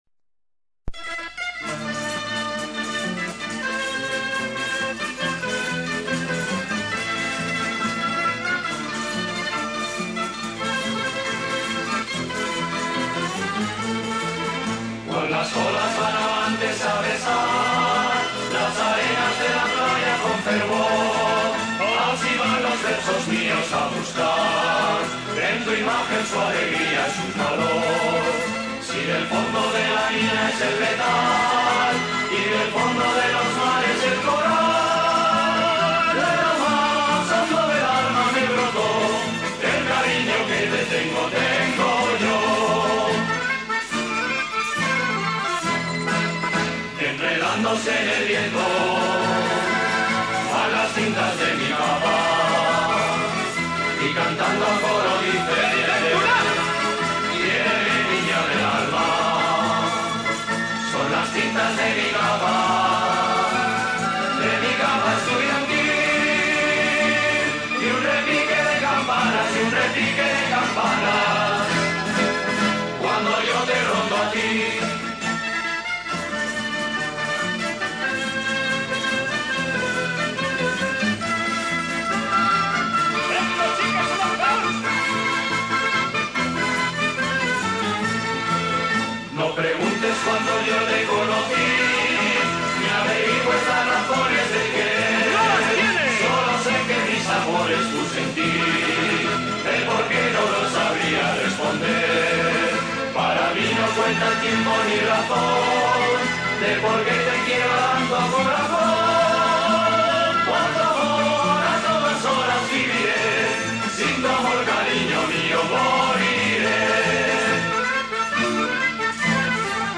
En verano de 1981 grabamos algunas canciones.